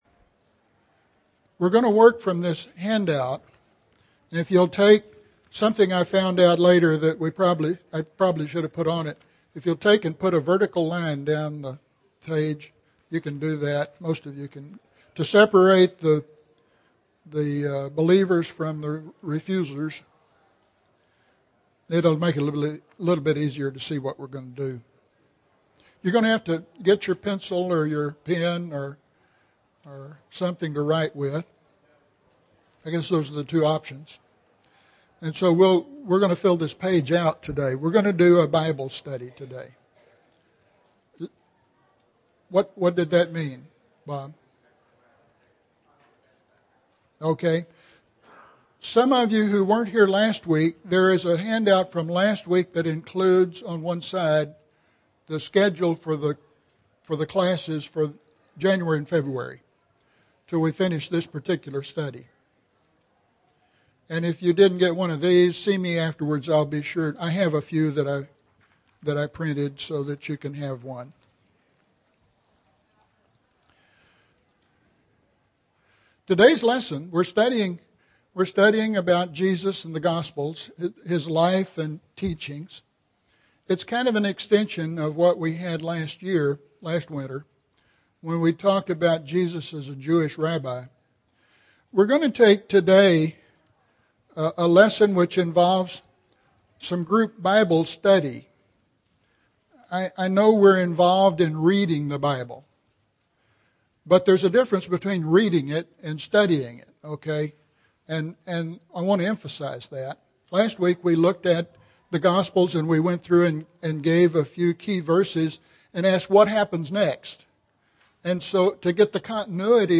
To Whom Has the Arm of the Lord Been Revealed (2 of 9) – Bible Lesson Recording
Sunday AM Bible Class